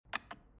start-spin.mp3